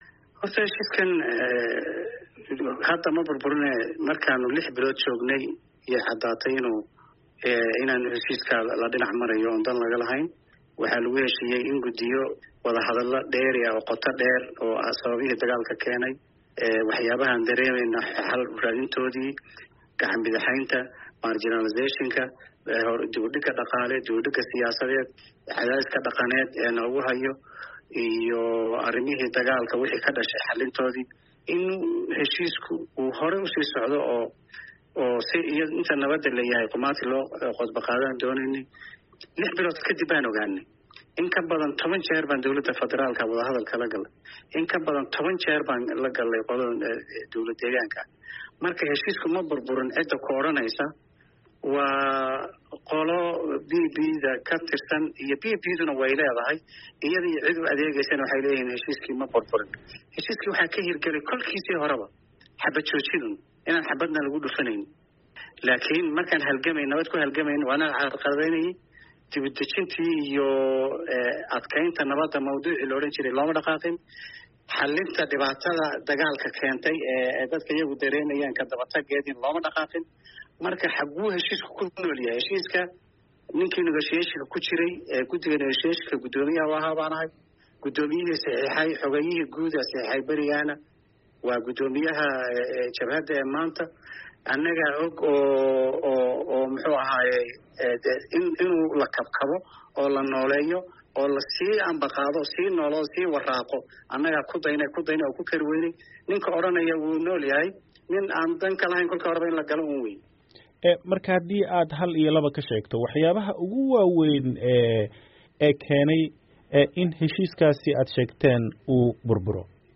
Wareysiha